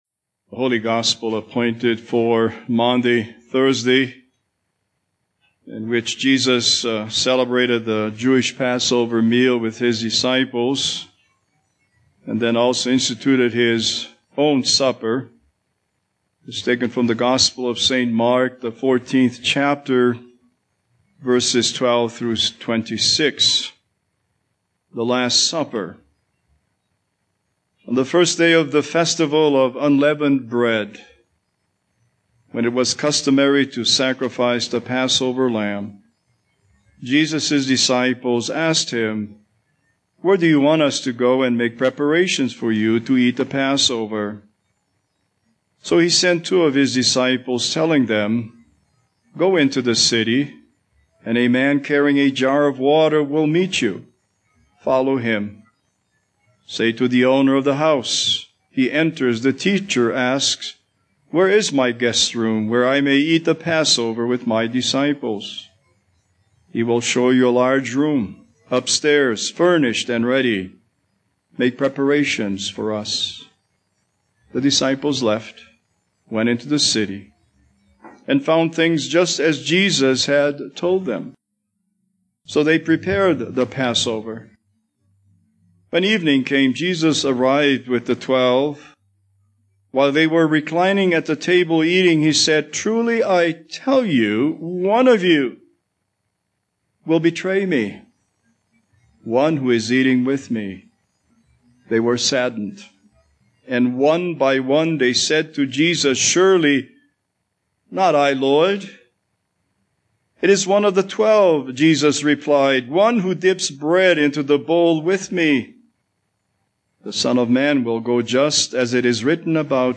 Holiday Sermons Passage: Mark 14:12-26 Service Type: Maundy Thursday